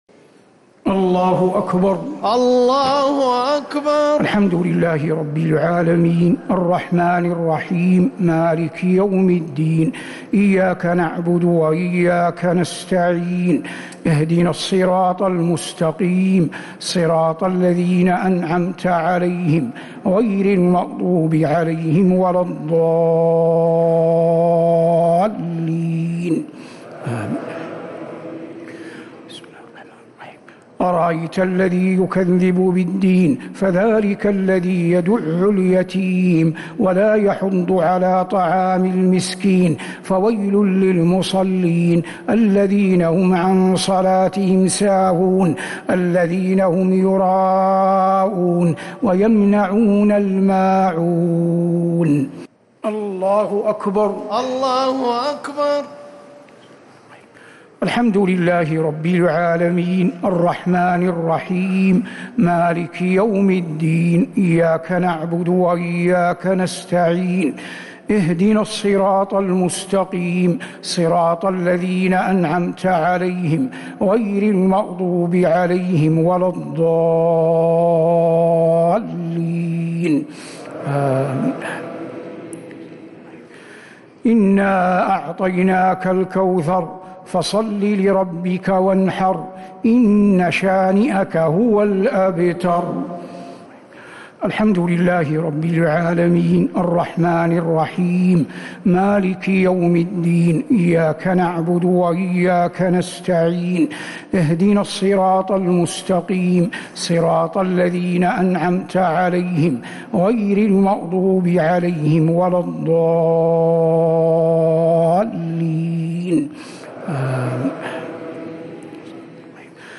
الشفع و الوتر ليلة 17 رمضان 1447هـ | Witr 17th night Ramadan 1447H > تراويح الحرم النبوي عام 1447 🕌 > التراويح - تلاوات الحرمين